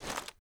gear_rattle_weap_medium_07.ogg